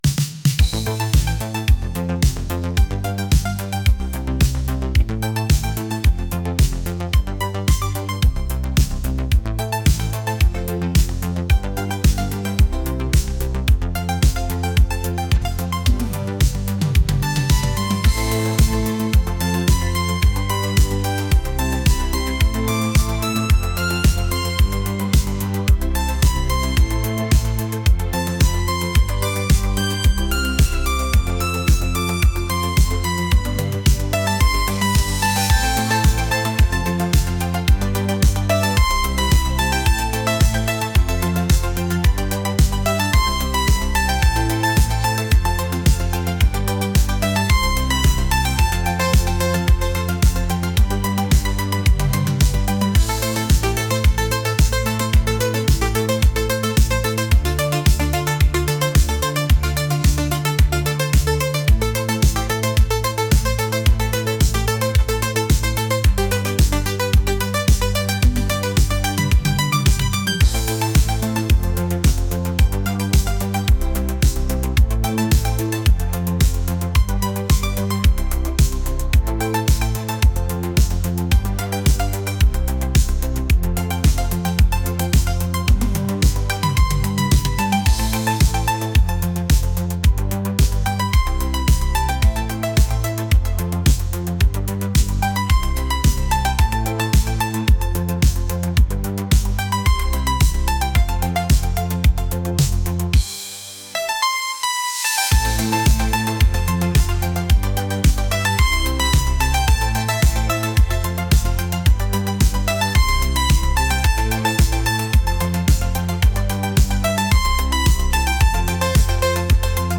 upbeat